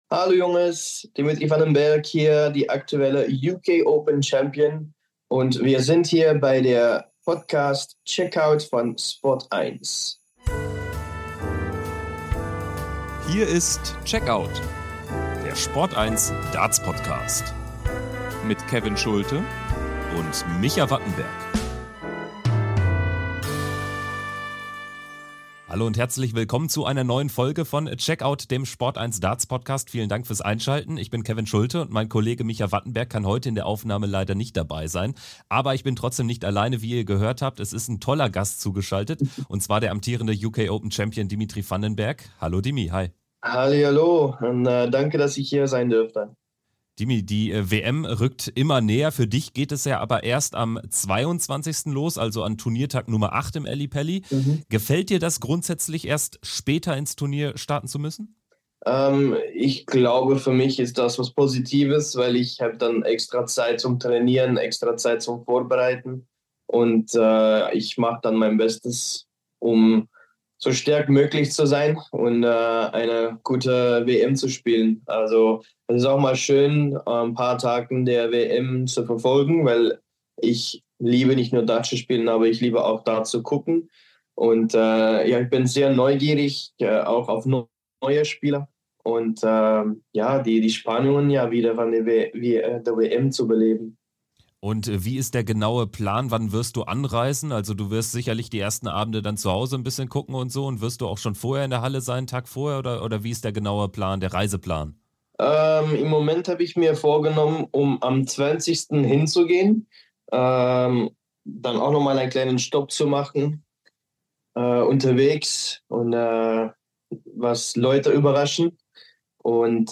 Das und vieles mehr hört ihr in diesem sehr offenen Interview mit der Nummer 11 der ...